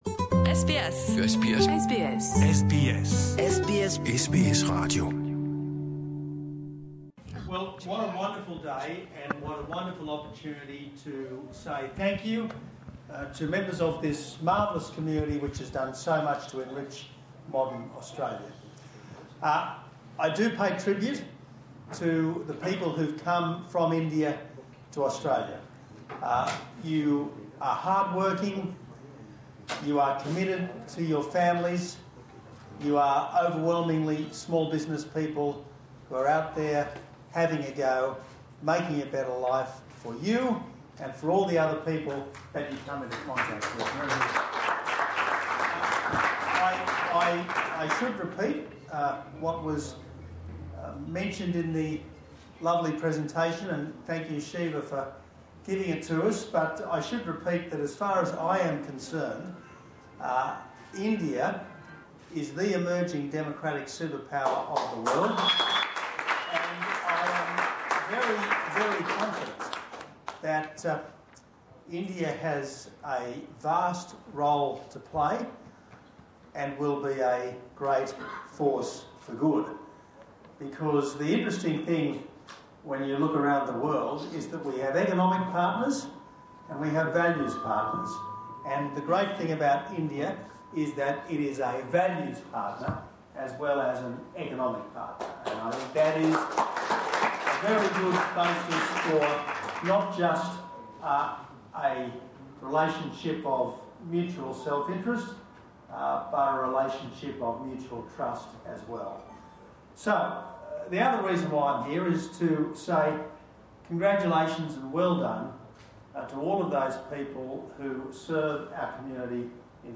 Former Prime Minister Tony Abbott at an Indian restaurant launch in Melbourne's south east on Saturday February 17 Source: SBS Punjabi